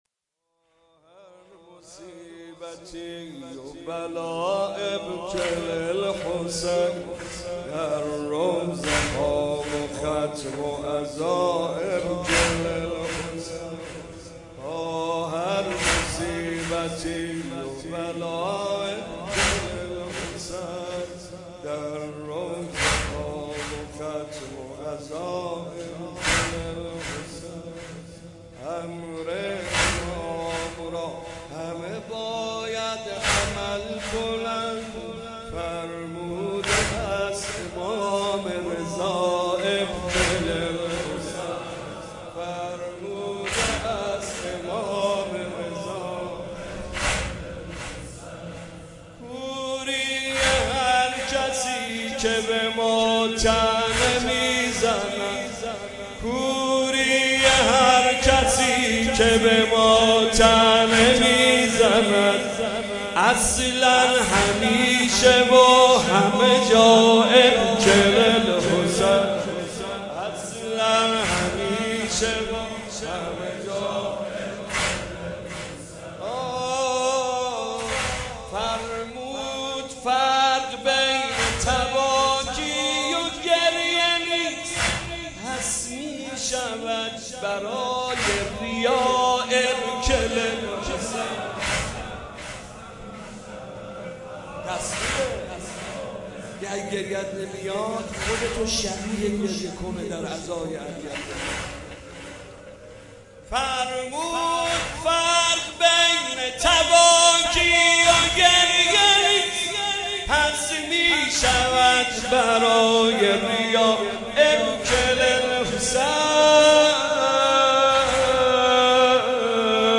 با هر مصیبتی و بلا ابک الحسین حاج محمدرضا طاهری شب دوم محرم 96/06/31 | ❤یک نت